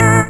orgTTE54014organ-A.wav